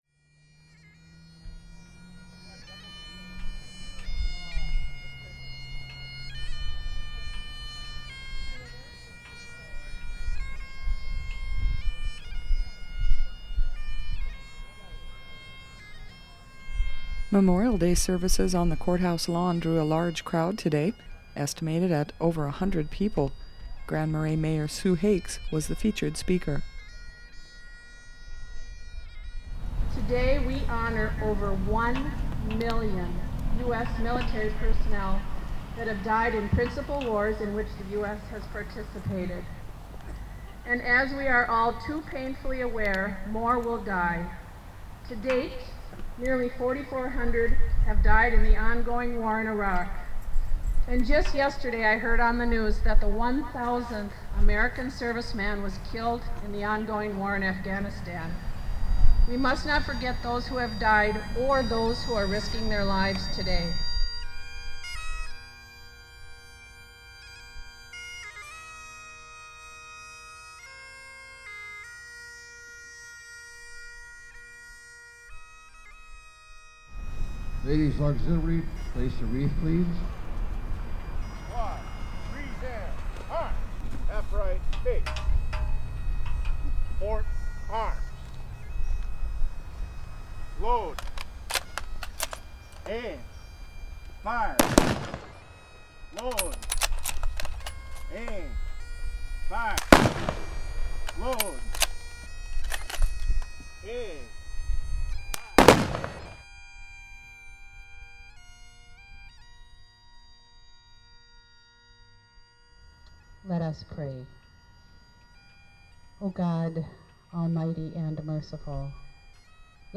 Memorial Day services on the Courthouse lawn in Grand Marais drew a large crowd, estimated at over one hundred people.